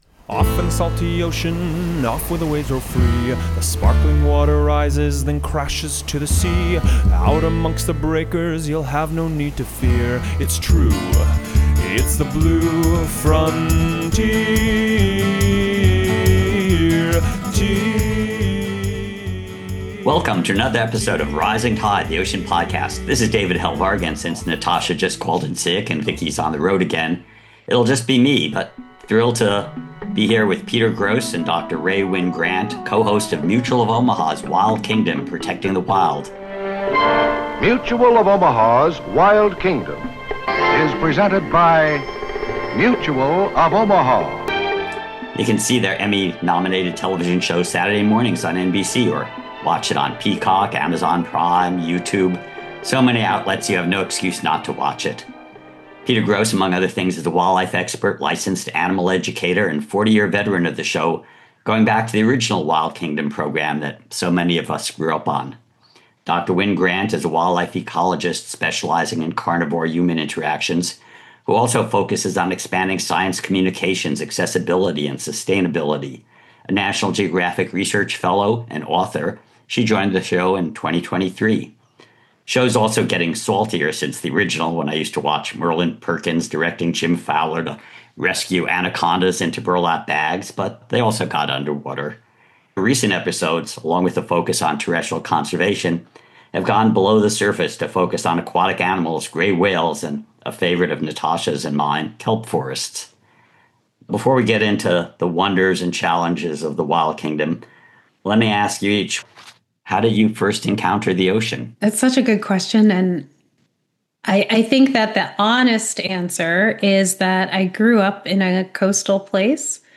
‘Wild Kingdom’ is also getting saltier over time with more ocean shows on gray whales, white sharks, coral reefs, and one of our favorites, kelp forests, including their most famous residents, the voracious marine weasels also known as sea otters. So, dive in for a fun and informative interview with these two land and sea stars.